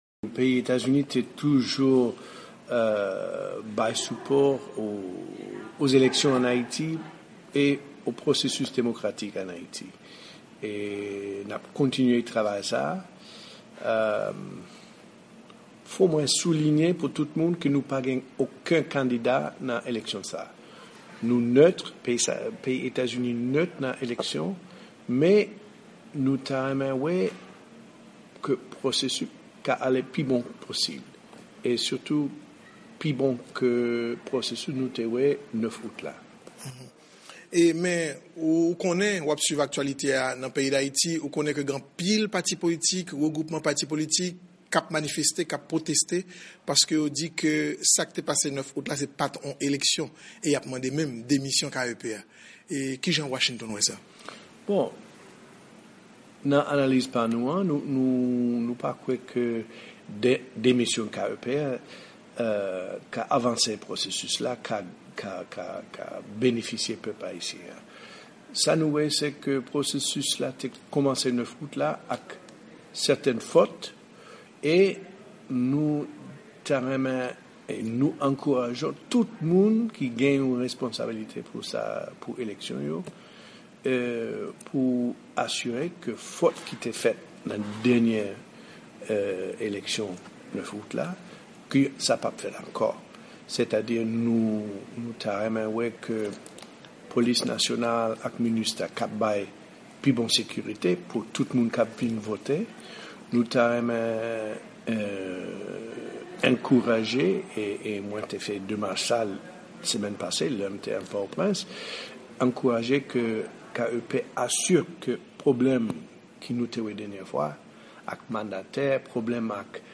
10-5-15 Interview Kenneth Merten with VOA-Creole